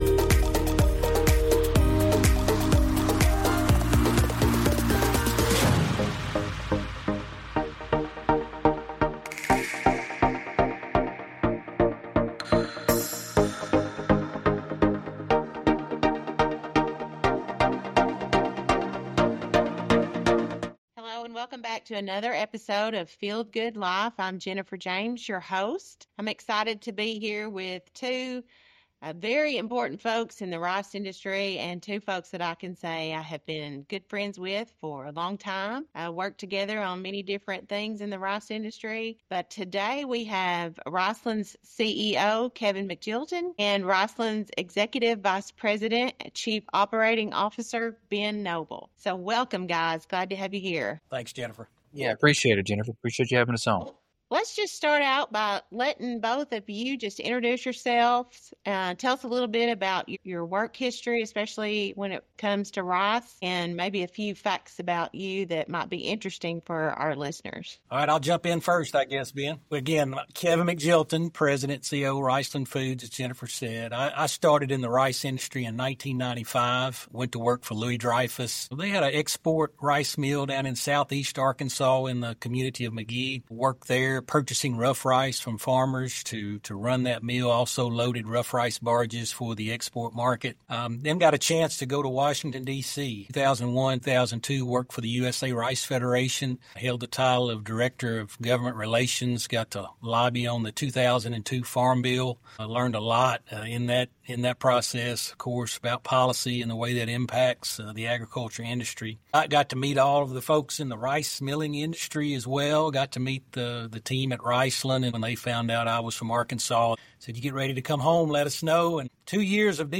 This conversation isn’t just about hunting.